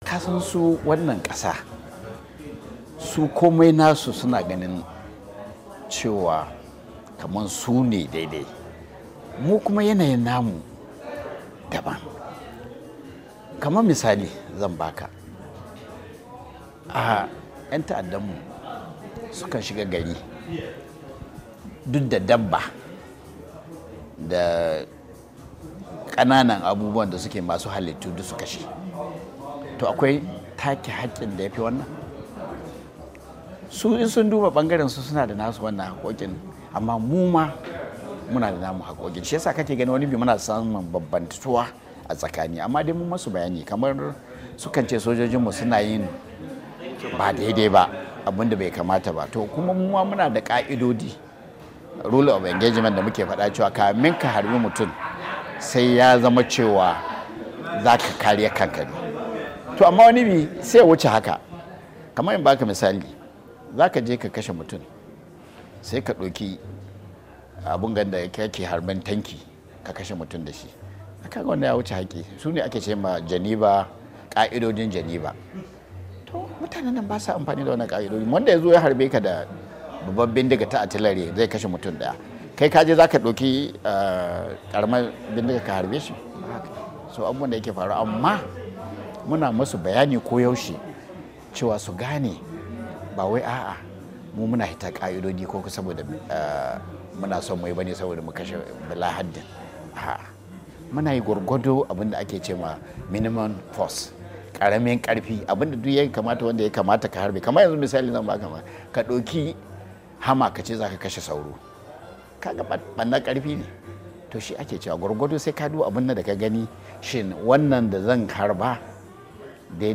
A cikin tattaunawar da ya yi da Muryar Amurka minstan tsaron Najeriya ya bayyana manufar yakin da su keyi da Boko Haram inda ya karyata zargin cewa sojojin Najeriya na yiwa mutane kisan kiyashi